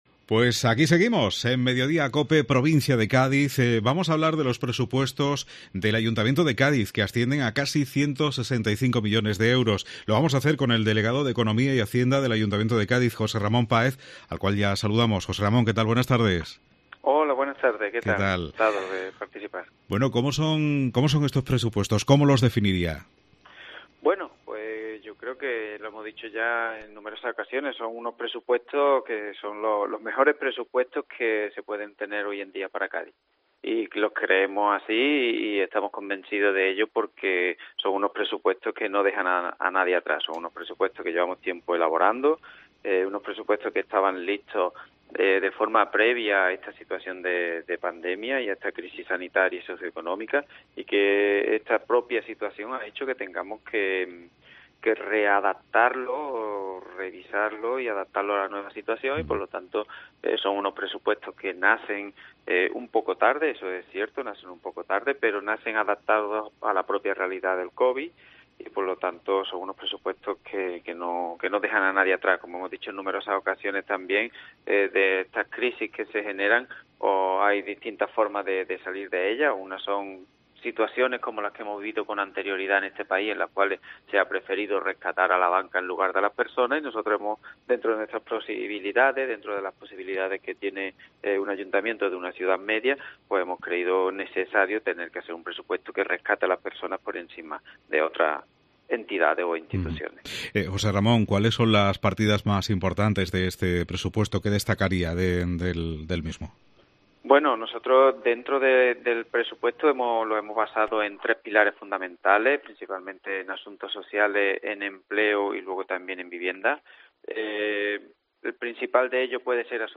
Entrevista José Ramón Páez, concejal de Hacienda del Ayuntamiento de Cádiz